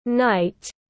Buổi đêm tiếng anh gọi là night, phiên âm tiếng anh đọc là /naɪt/
Night /naɪt/
Night.mp3